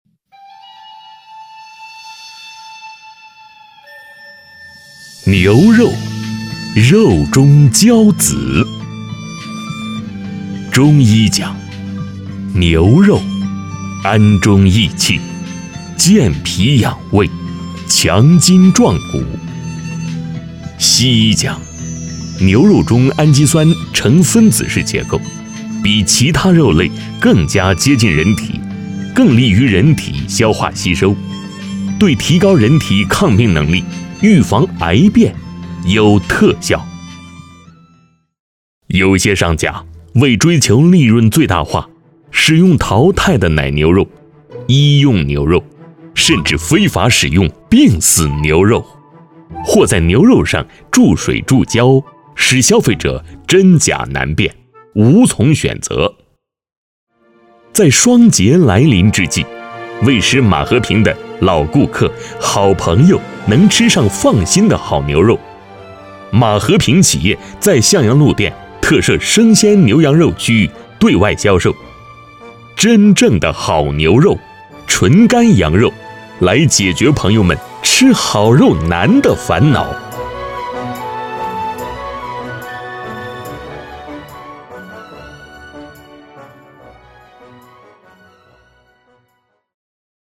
男46-【企宣平稳】餐饮-牛肉介绍
男46大气专题 46
男46--企宣平稳-餐饮-牛肉介绍.mp3